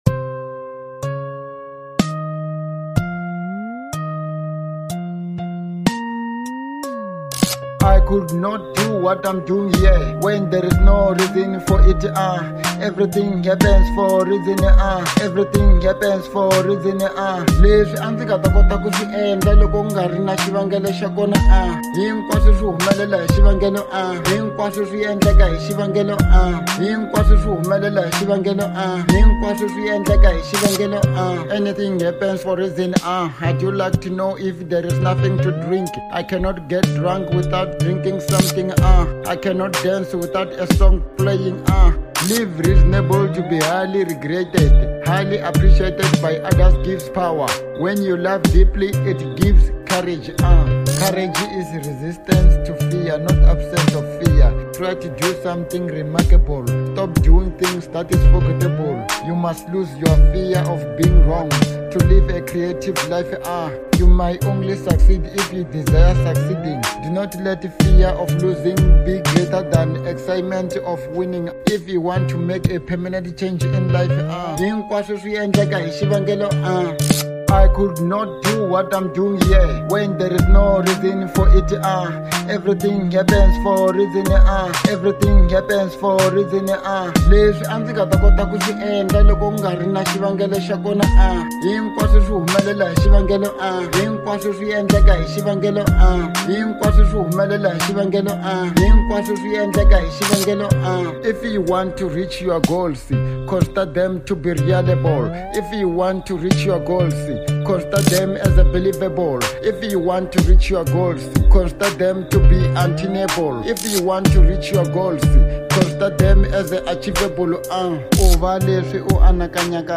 03:38 Genre : Hip Hop Size